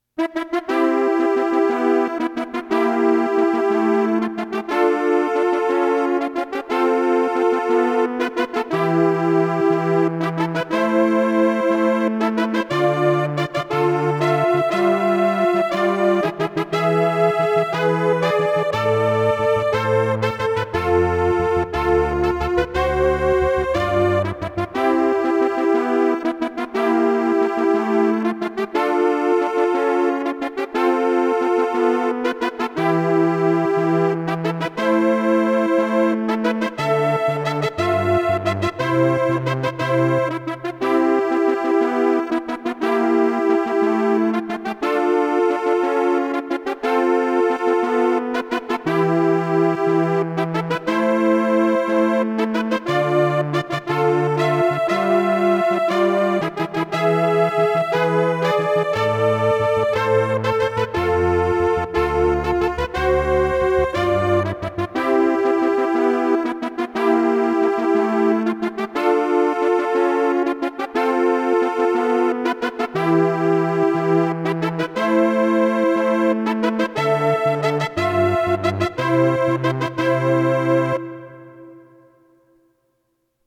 As recorded from the original Roland MT-32 score